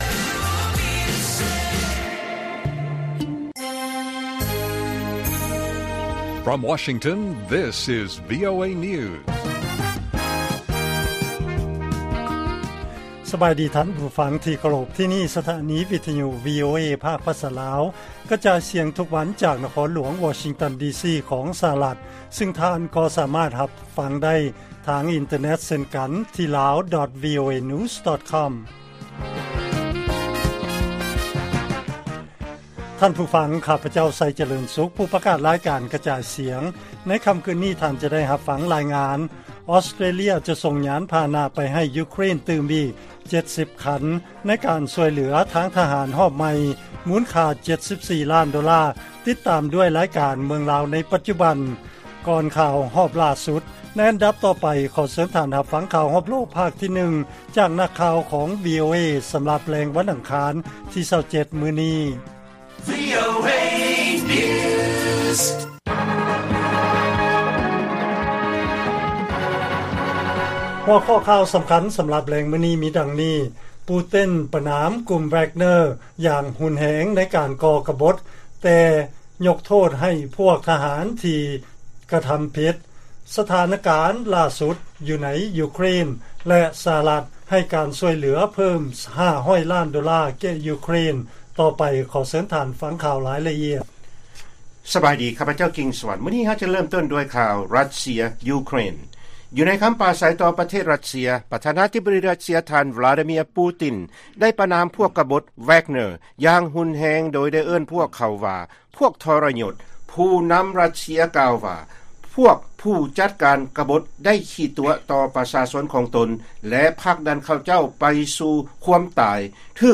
ລາຍການກະຈາຍສຽງຂອງວີໂອເອ ລາວ: ປູຕິນ ປະນາມກຸ່ມແວກເນີຢ່າງແຮງ ໃນການກໍ່ກະບົດ ແຕ່ຍົກໂທດໃຫ້ພວກທະຫານ ທີ່ກະທຳຜິດ